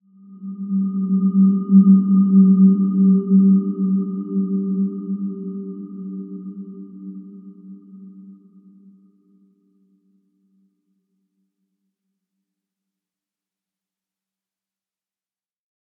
Dreamy-Fifths-G3-f.wav